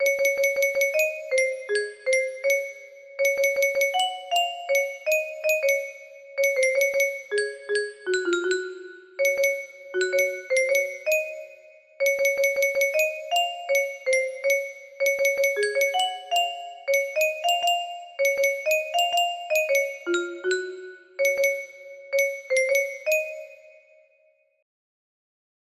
code: C# major